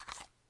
武器（1911手枪） " 弹匣取出 01